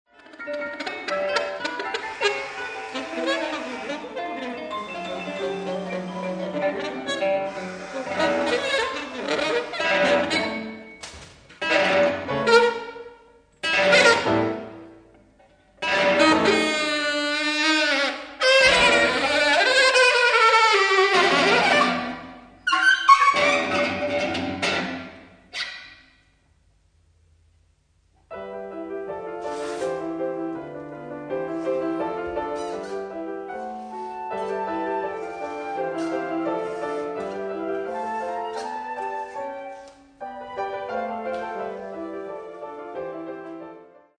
From track 2, Copenhagen 1981